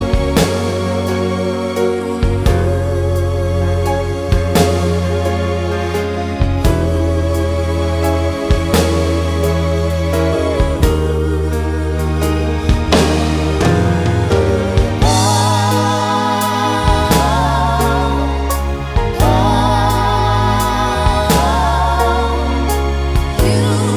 Live Version With No Sax Solo Pop (1960s) 3:34 Buy £1.50